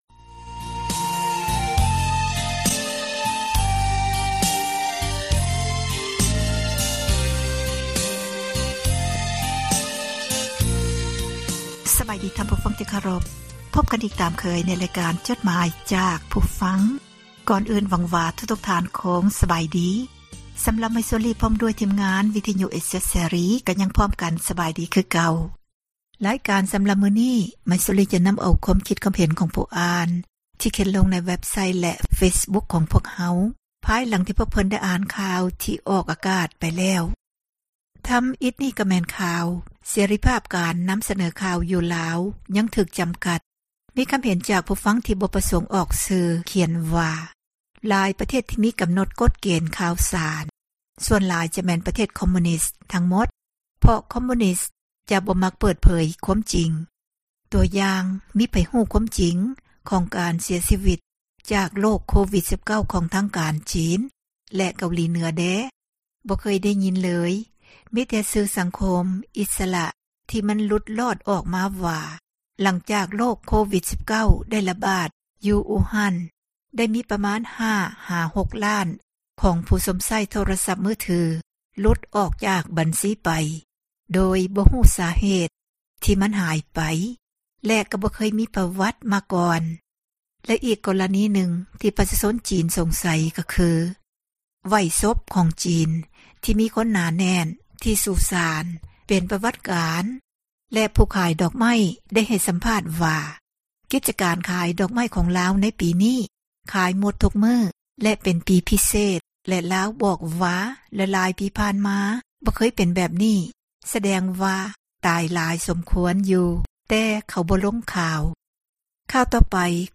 ອ່ານຈົດໝາຍ, ຄວາມຄຶດຄວາມເຫັນ ຂອງທ່ານ ສູ່ກັນຟັງ